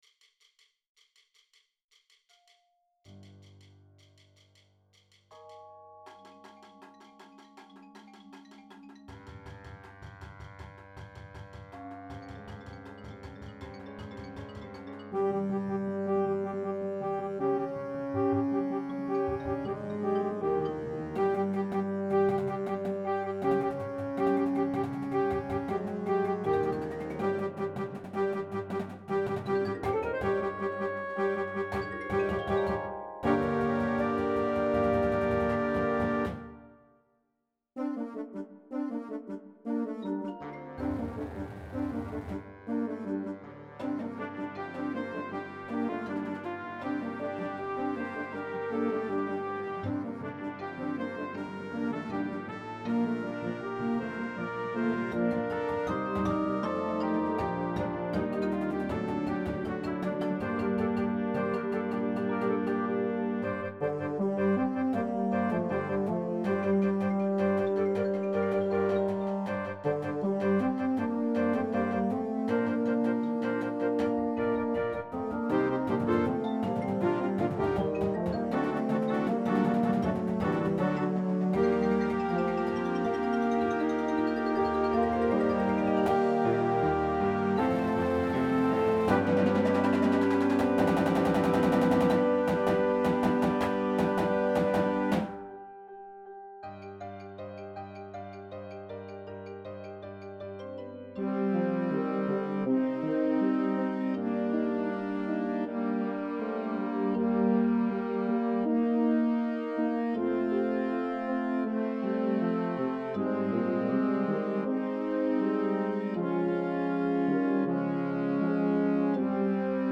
contemporary Marching Band Show scored for smaller bands